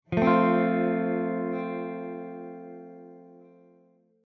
They are three tones or more played together at the same time.
F Chord
fchord.mp3